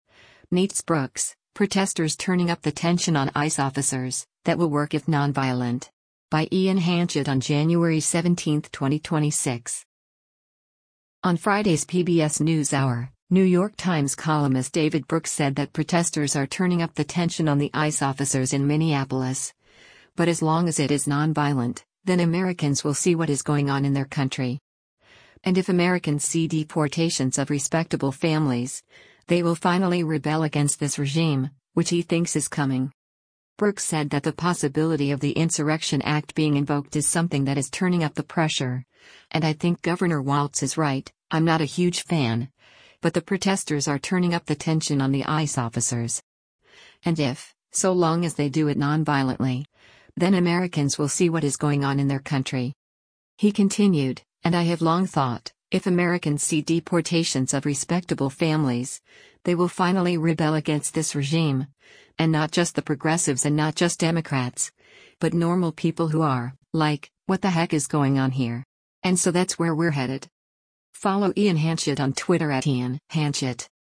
On Friday’s “PBS NewsHour,” New York Times columnist David Brooks said that “protesters are turning up the tension on the ICE officers” in Minneapolis, but as long as it is nonviolent, “then Americans will see what is going on in their country.”